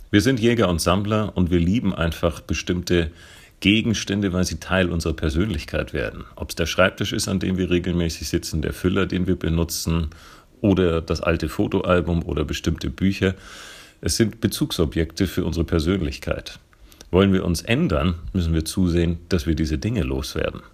Teaser mp3 mus